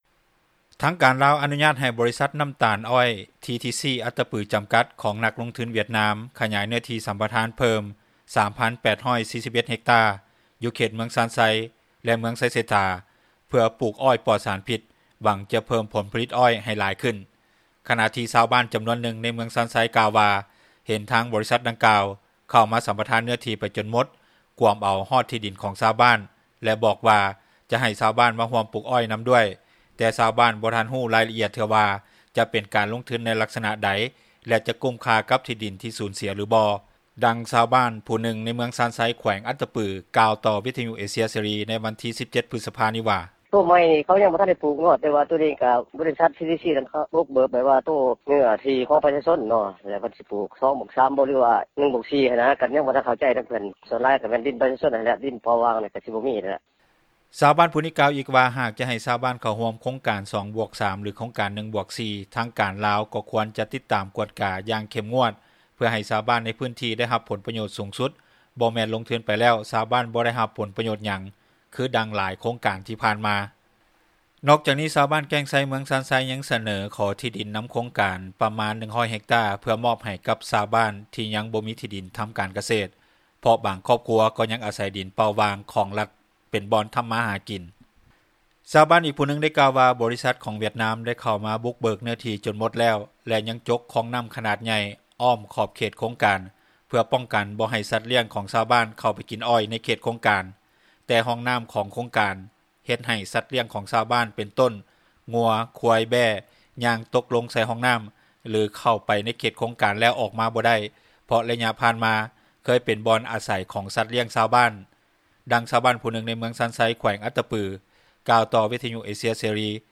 ດັ່ງຊາວບ້ານຜູ້ນຶ່ງ ໃນເມືອງຊານໄຊ ແຂວງອັດຕະປື ກ່າວຕໍ່ວິທຍຸເອເຊັຽເສຣີໃນວັນທີ 17 ພຶສພານີ້ວ່າ:
ດັ່ງຊາວບ້ານຜູ້ນຶ່ງ ໃນເມືອງຊານໄຊ ແຂວງອັດຕະປື ກ່າວຕໍ່ວິທຍຸເອເຊັຽເສຣີໃນວັນທີ 17 ພຶສພາ ນີ້ວ່າ: